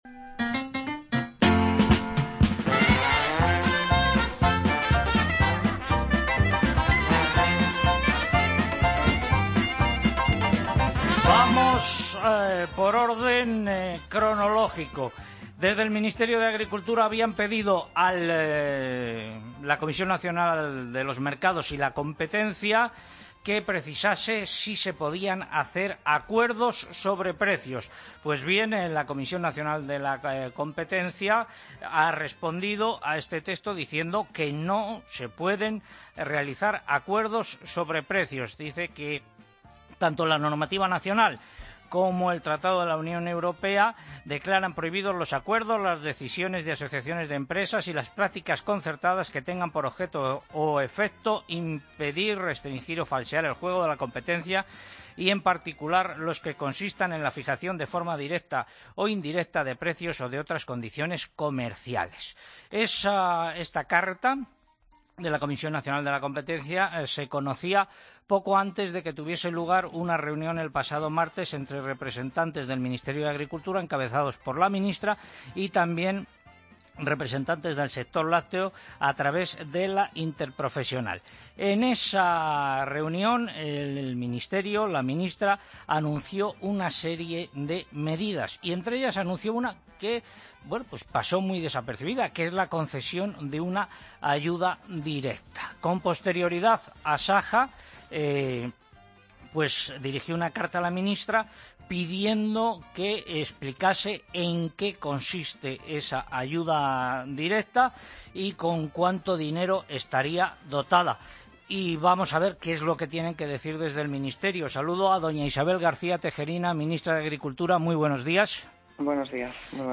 Escucha la entrevista a la ministra de Agricultura en 'Agropopular'